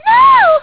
Mario Kart DS Sounds